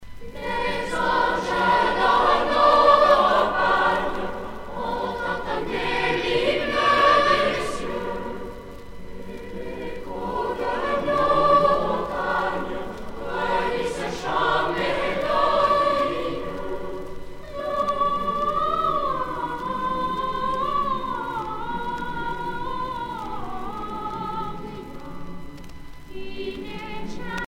Noël
Genre strophique
Petits Chanteurs de Saint-Laurent (Les)
Pièce musicale éditée